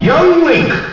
The announcer saying Young Link's name in Super Smash Bros. Melee.
Young_Link_Announcer_SSBM.wav